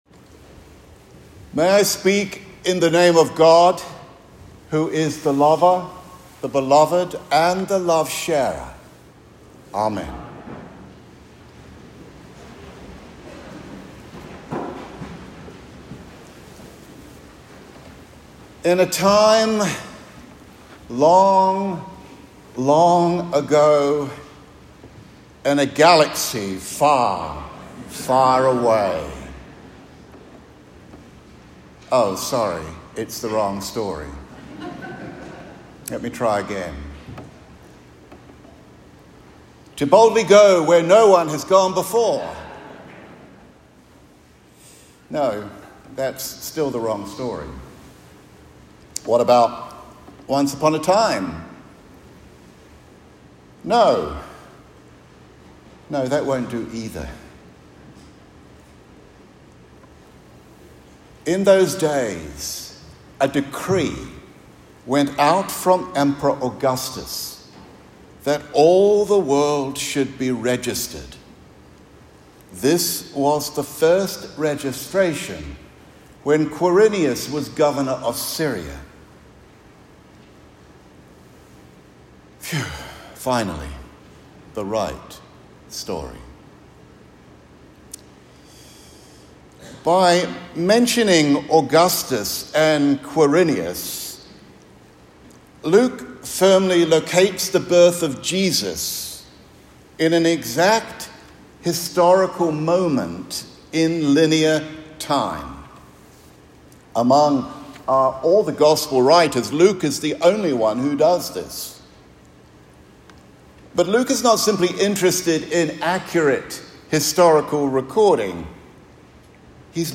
Prayers and Sermon
Recording of the Sermon